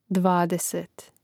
dvádesēt dvadeset